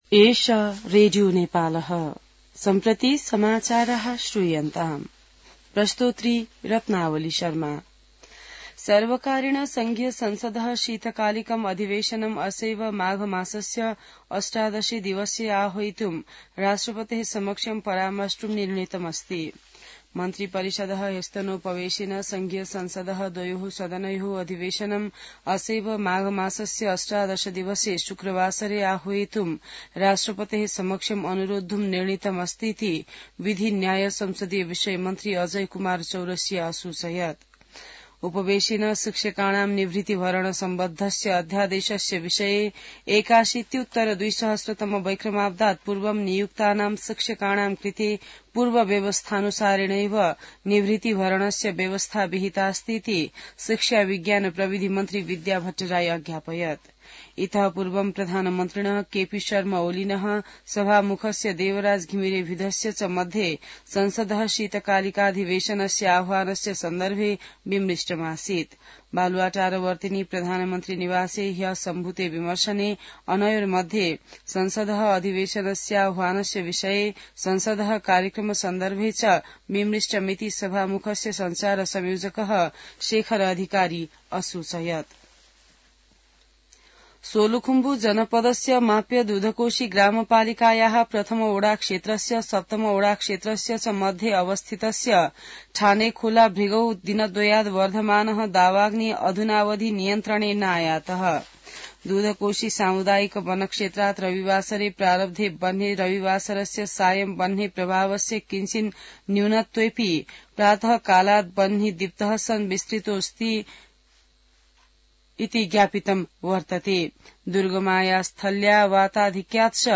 संस्कृत समाचार : ९ माघ , २०८१